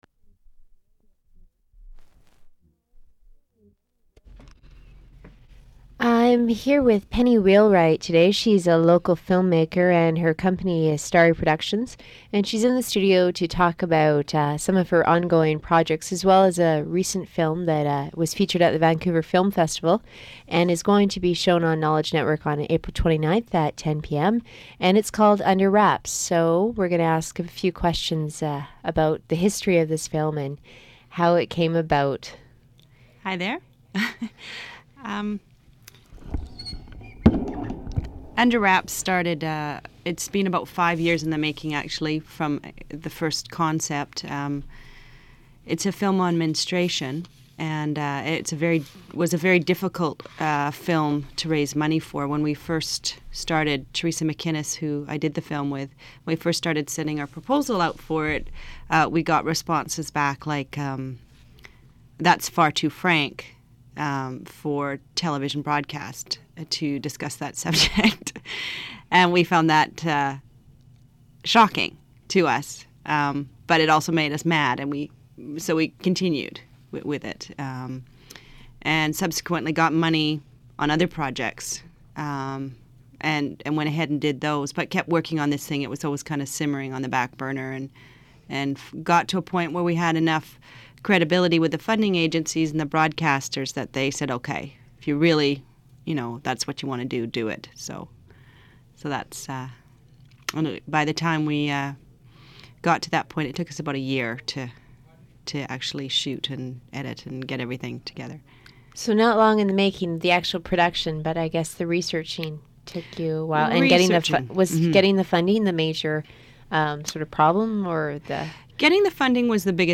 Recording of an interview with screenwriter